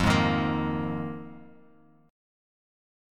Fm11 Chord
Listen to Fm11 strummed